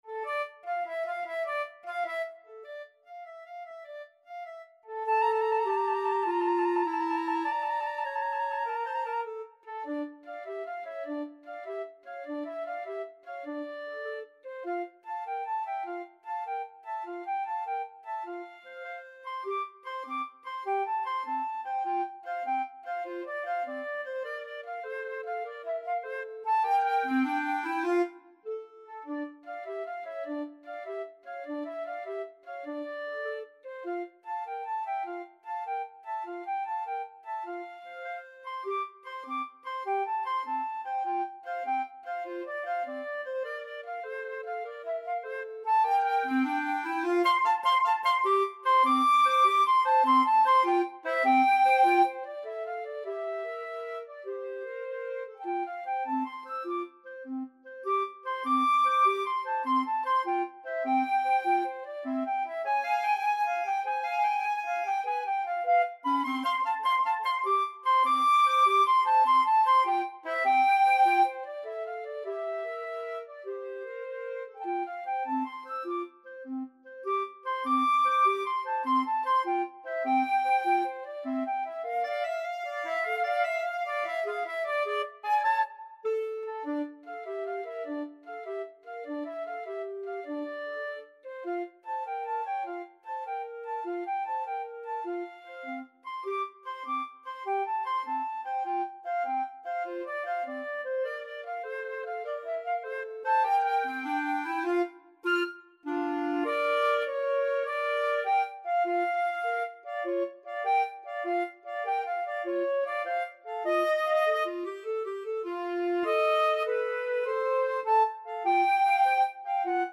Free Sheet music for Flute-Clarinet Duet
Allegro moderato . = c.100 (View more music marked Allegro)
D minor (Sounding Pitch) E minor (Clarinet in Bb) (View more D minor Music for Flute-Clarinet Duet )
6/8 (View more 6/8 Music)
Flute-Clarinet Duet  (View more Intermediate Flute-Clarinet Duet Music)
Pop (View more Pop Flute-Clarinet Duet Music)